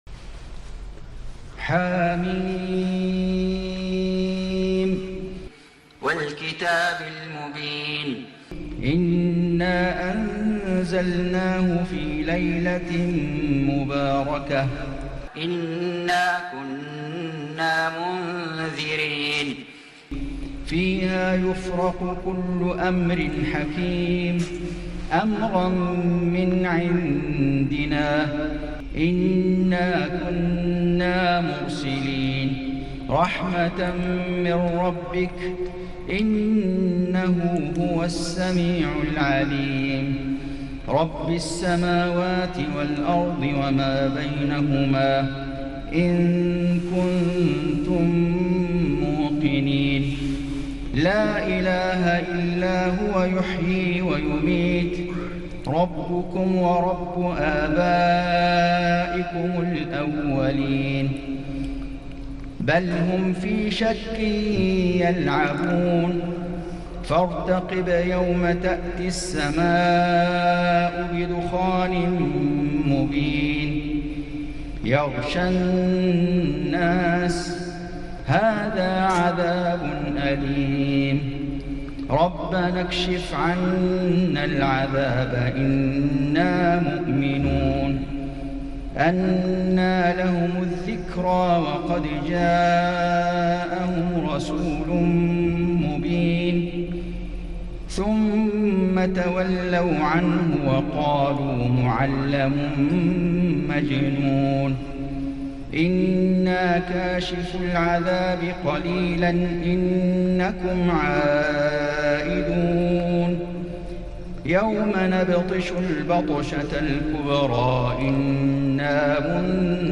سورة الدخان > السور المكتملة للشيخ فيصل غزاوي من الحرم المكي 🕋 > السور المكتملة 🕋 > المزيد - تلاوات الحرمين